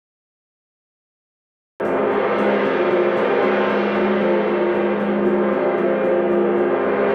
petit_stat_moy.wav